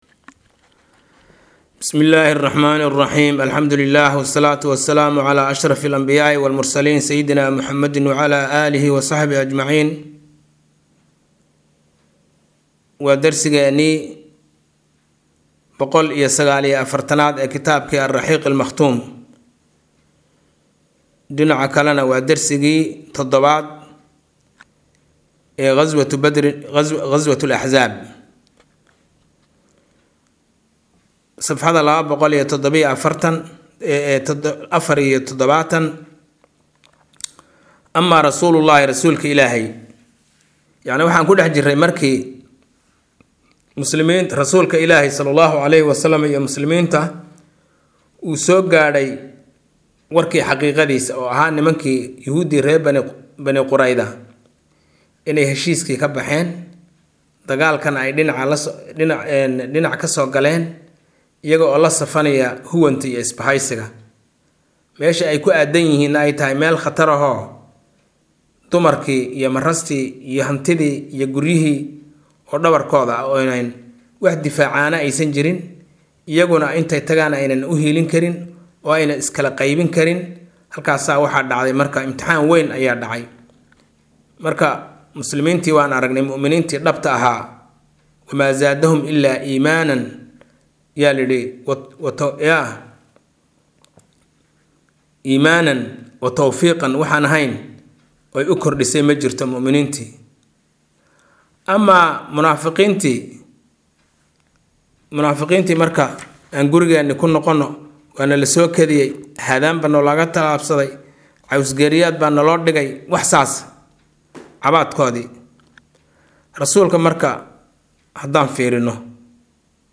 Maqal– Raxiiqul Makhtuum – Casharka 149aad